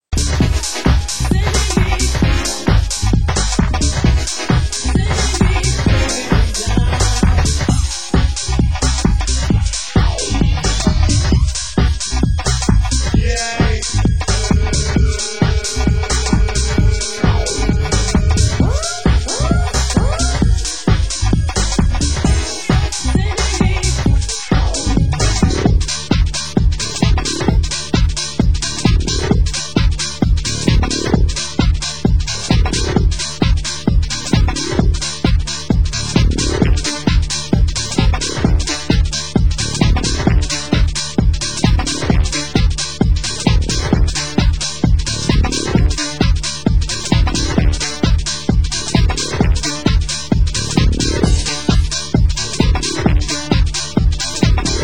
Genre: UK Garage
Genre: UK House